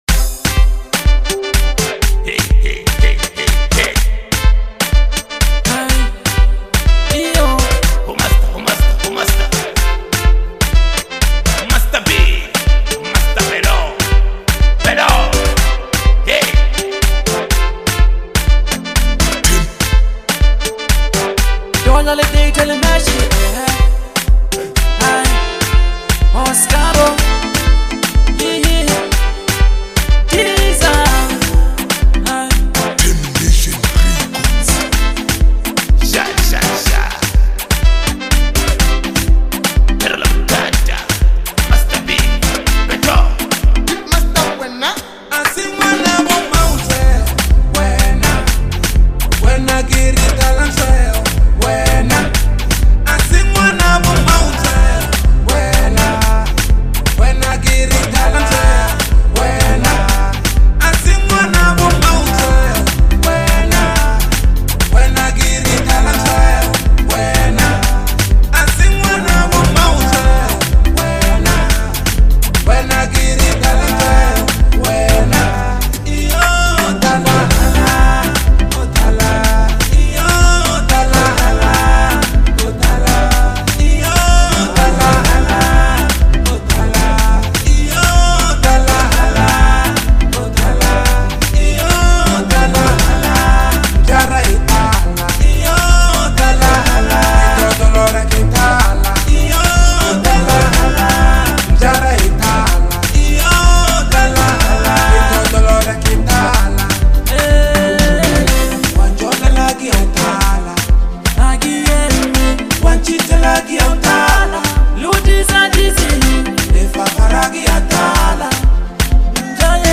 a radio and club-friendly Jam